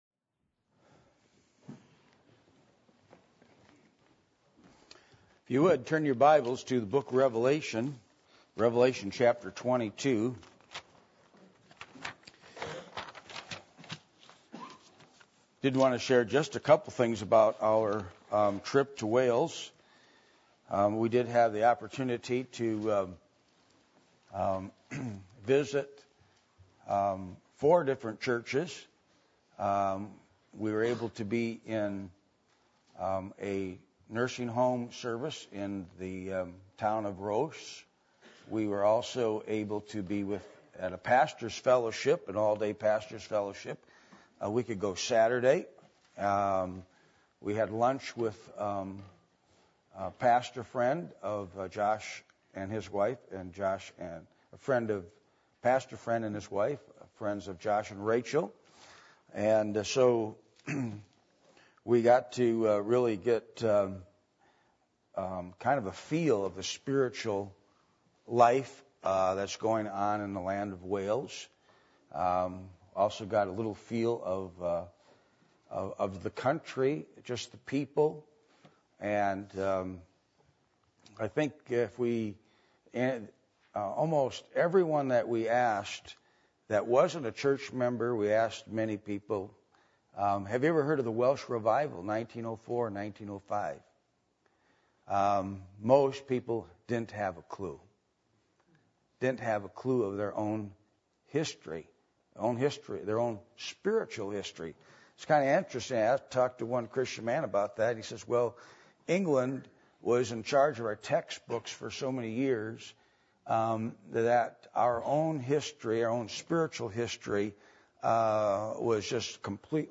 Passage: Revelation 22:1-21 Service Type: Sunday Morning